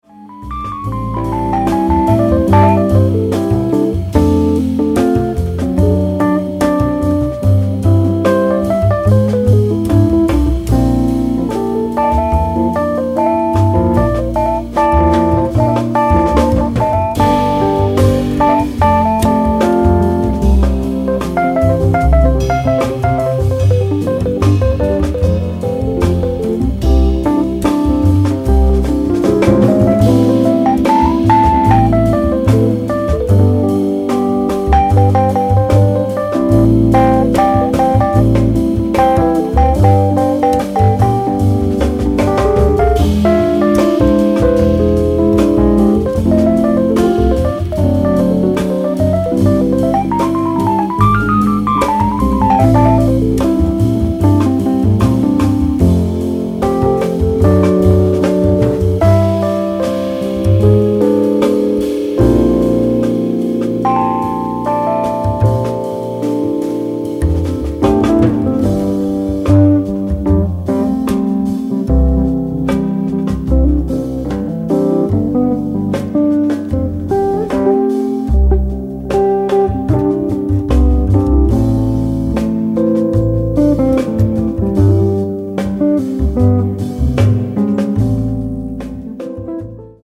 guitar
Fender Rhodes
bass
drums